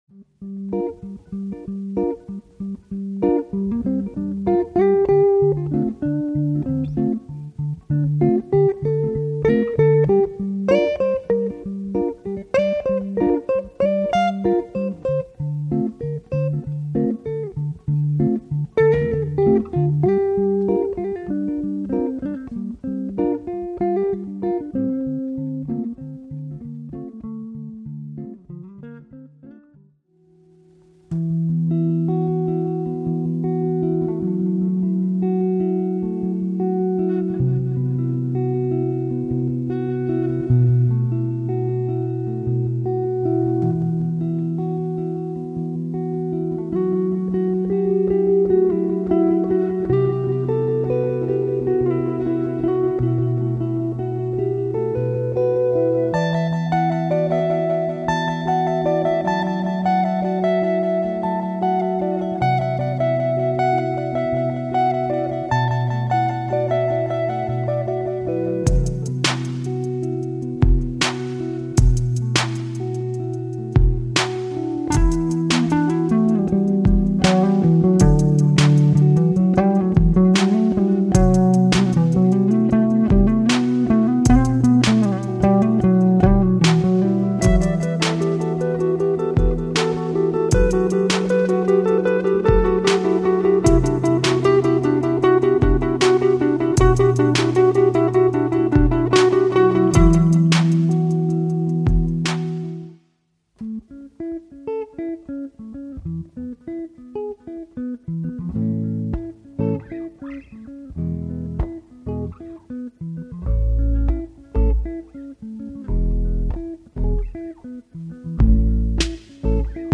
Male Solo Looping Guitarist Based In London
rhythmic beats and vibrant melodies
Guitar / Loop Pedal